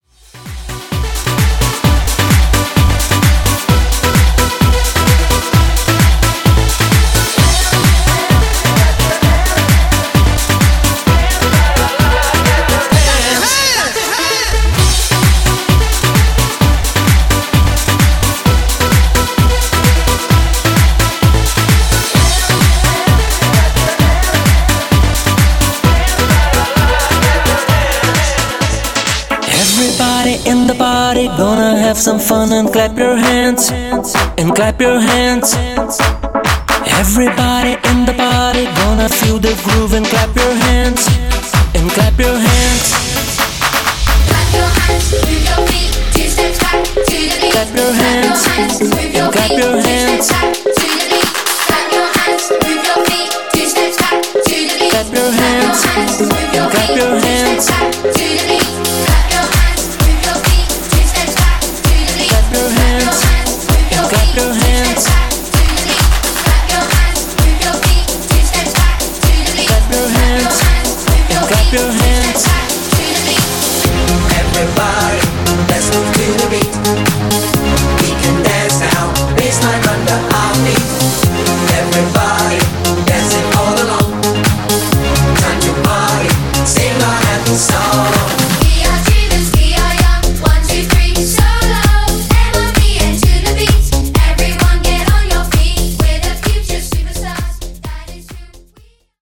Wordplay Segue Edit)Date Added